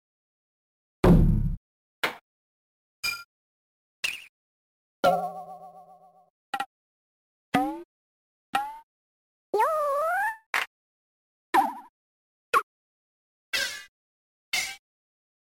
Asian Drums in WarioWare: D.I.Y.
WWDIY_asianDrumsInstruments.mp3